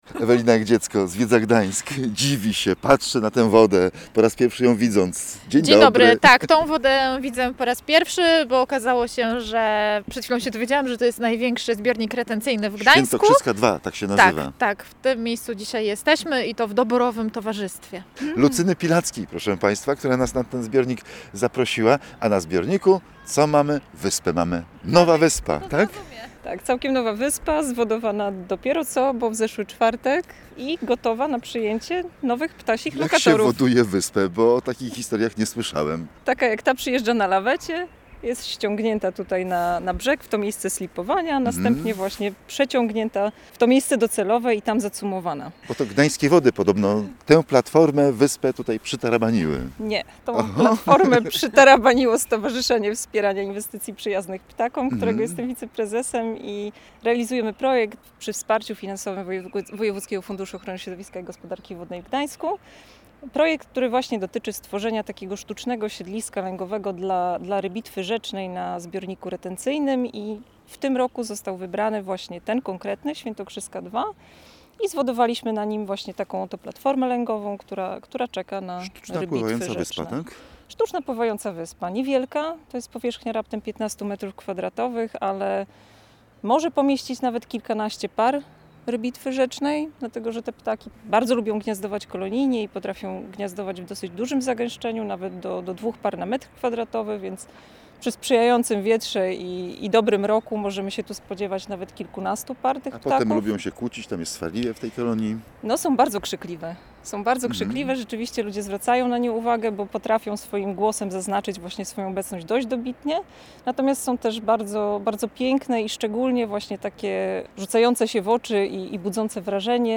Spotkaliśmy się przy zbiorniku retencyjnym Świętokrzyska II w Gdańsku, na którym stowarzyszenie umieściło sztuczną wyspę, miejsce lęgowe dla rybitw rzecznych.